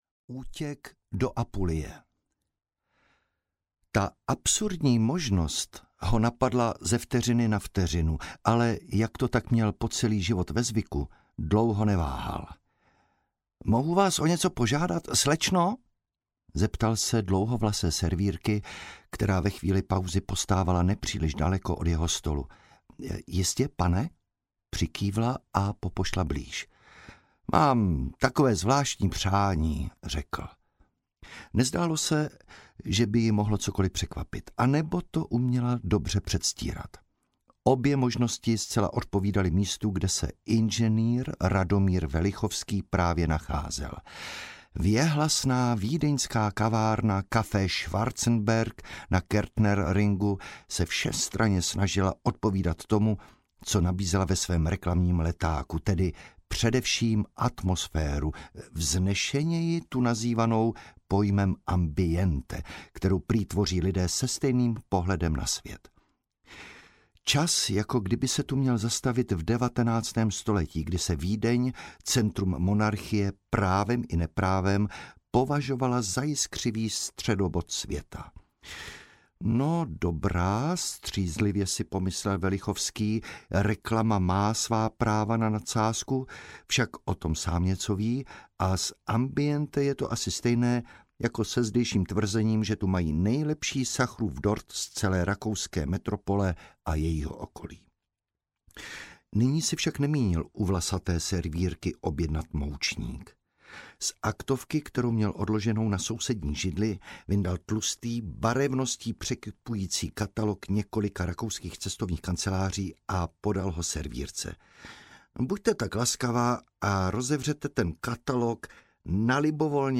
Doteky stínů audiokniha
Ukázka z knihy
Čtvrté pokračování série DOTEKY Romana Cílka přináší dalších devět detektivních povídek, napsaných podle skutečných příběhů z kriminálních archivů v podání devíti špičkových českých interpretů.